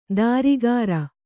♪ dārigāra